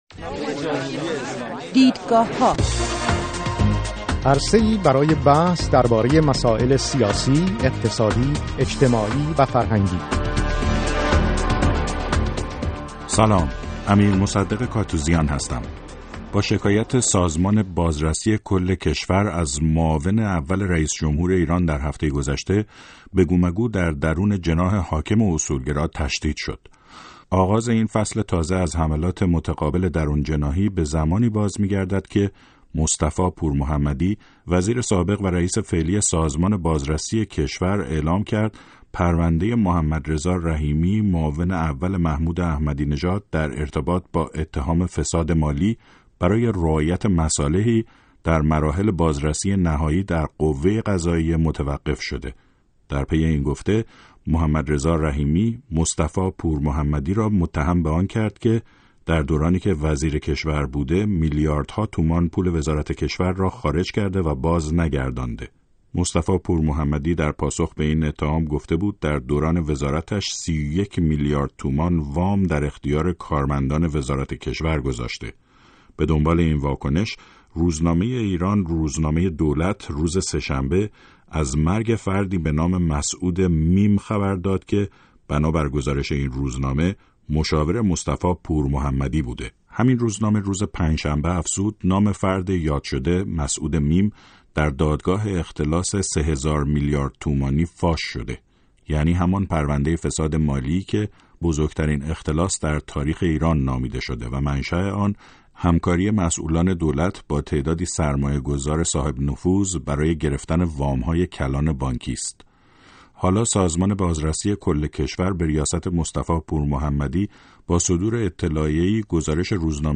در برنامه این هفته دیدگاهها با سه میهمان برنامه به ریشه یابی اختلاف میان جناح راست جمهوری اسلامی ایران پرداخته ایم.